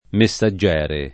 messaggere [ me SS a JJ$ re ]